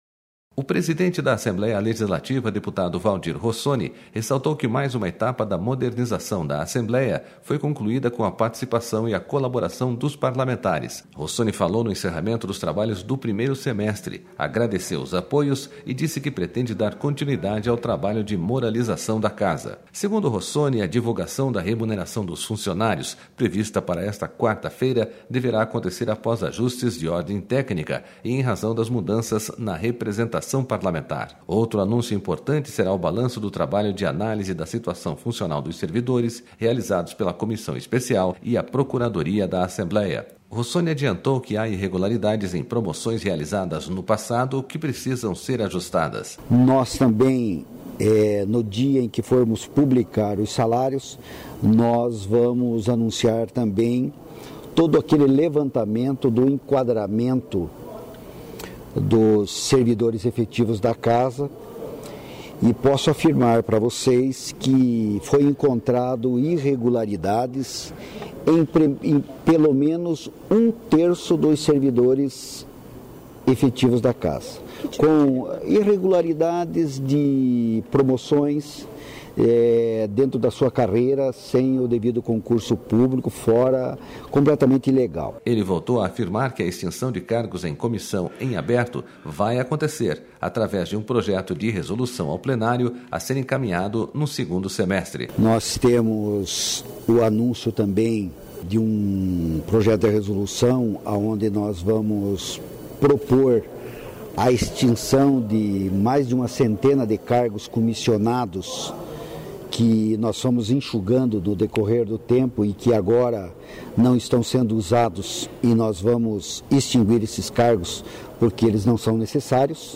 Rossoni falou no encerramento dos trabalhos do primeiro semestre, agradeceu os apoios e disse que pretende dar continuidade ao trabalho de moralização da Casa.//
O presidente da Assembleia falou ao final da sessão desta quarta-feira, que marca o início do recesso parlamentar de julho.//